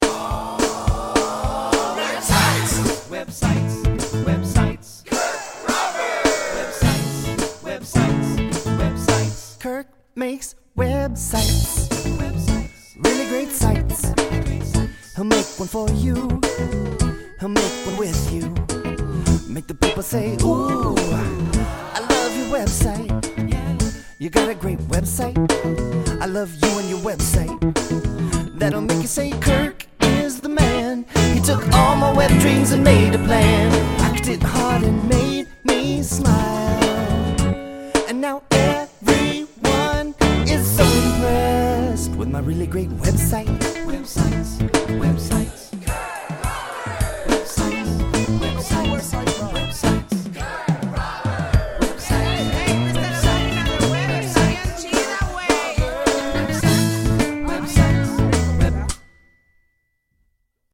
lead vocals
backing vocals